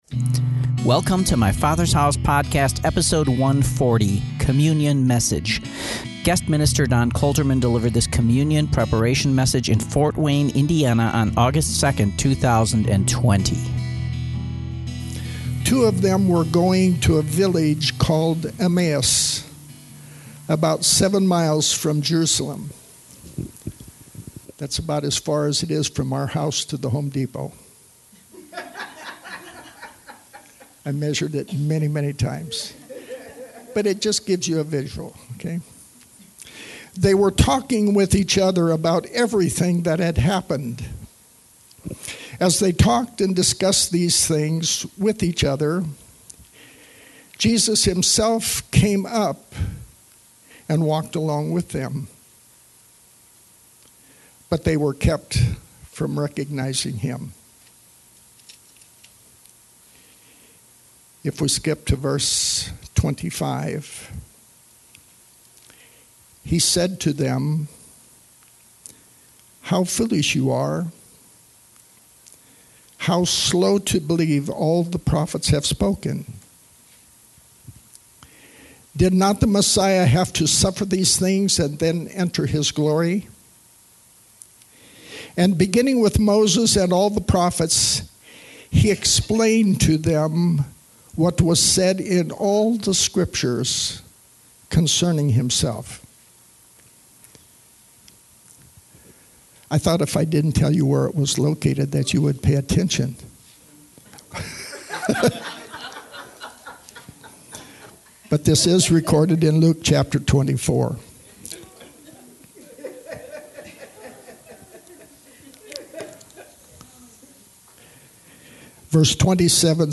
Bonus: Communion Message